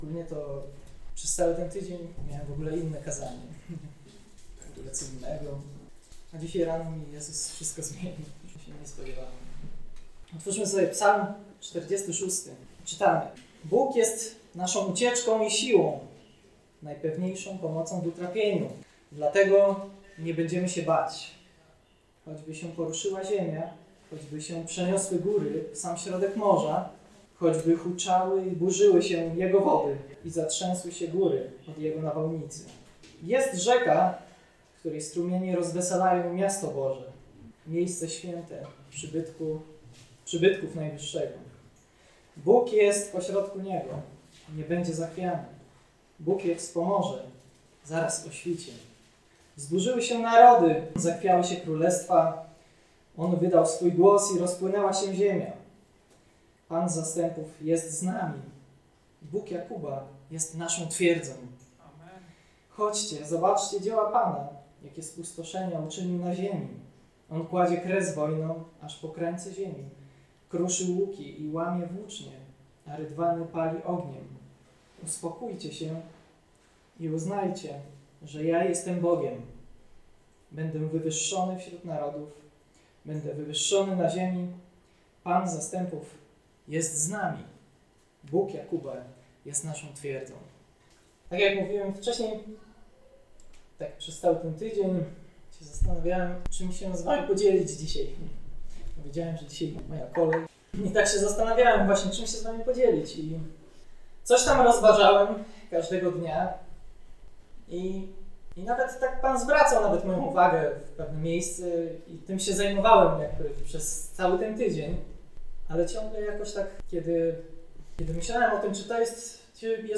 Posłuchaj kazań wygłoszonych w Zborze Słowo Życia w Olsztynie. Kazanie